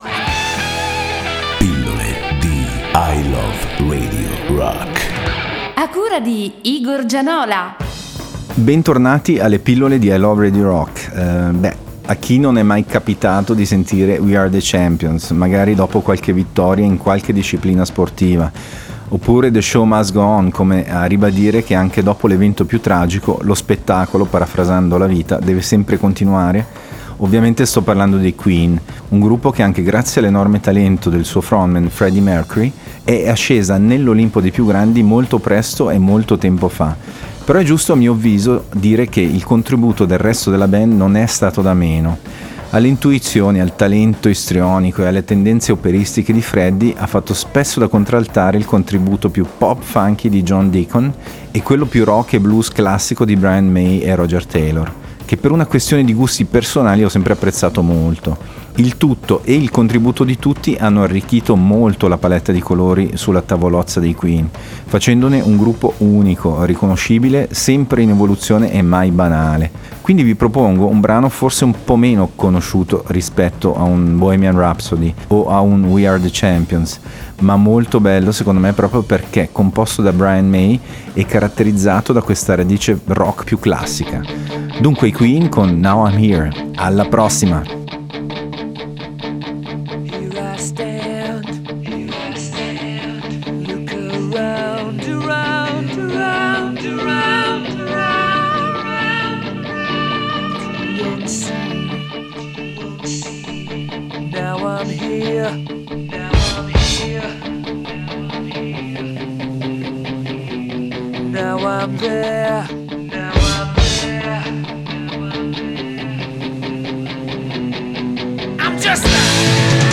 Il miglior programma di rock duro della Svizzera italiana.
ROCK